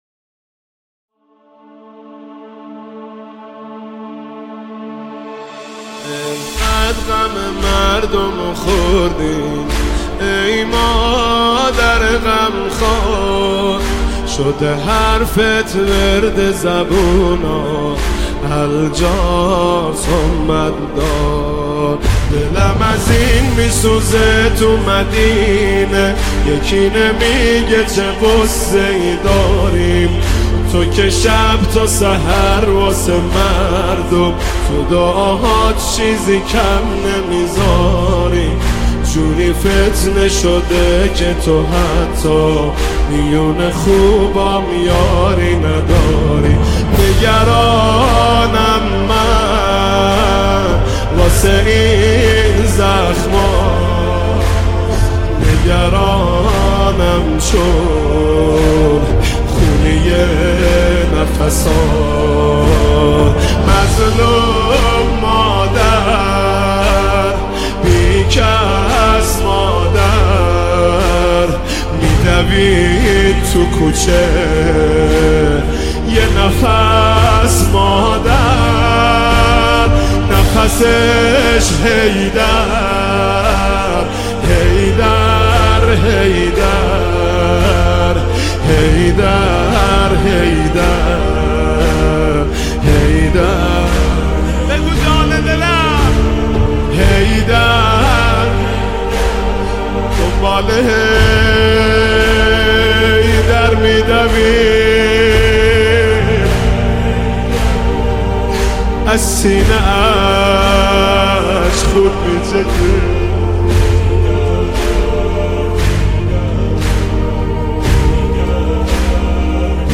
برچسب ها: مرثیه سرایی ، شهادت حضرت فاطمه (س) ، مداحی اهل بیت